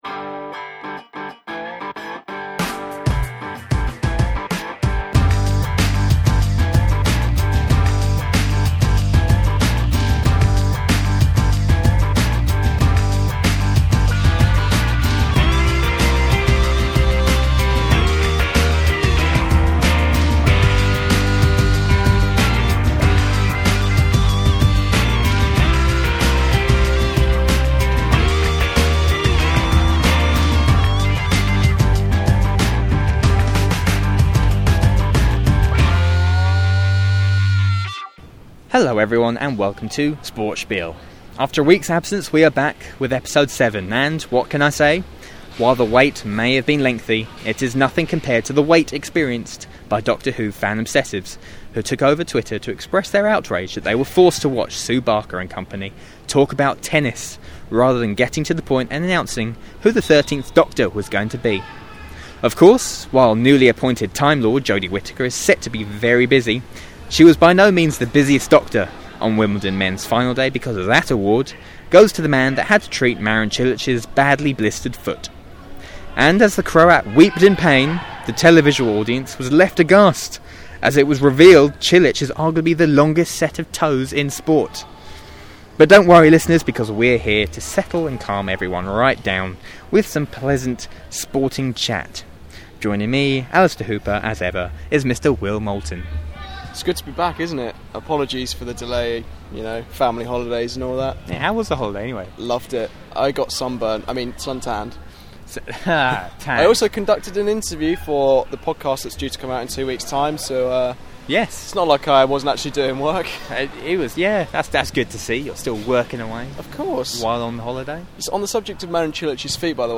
a podcast full of sporting discussion and feature interviews.